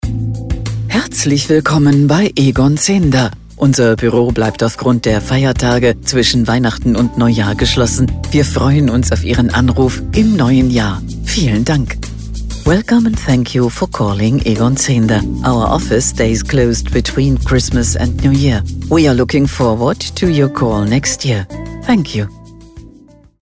AB Ansage: Feiertage für Egon Zehnder